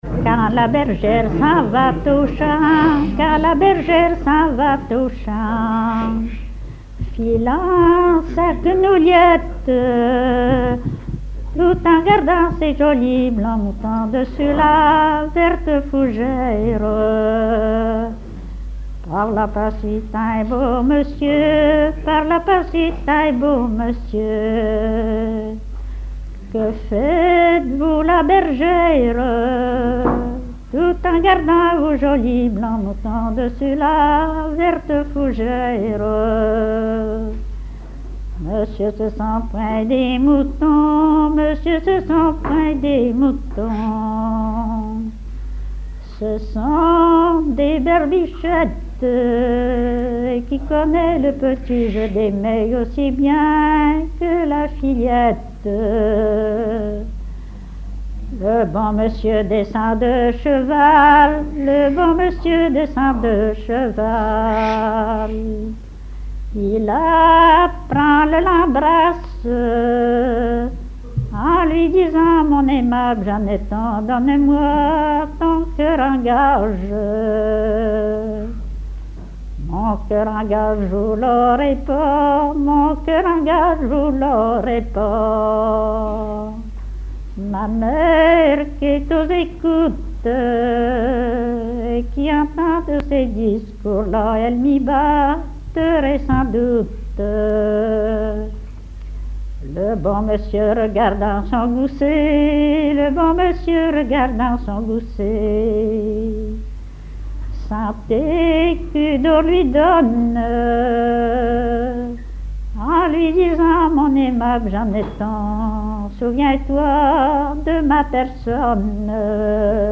Genre laisse
à la salle d'Orouët
Pièce musicale inédite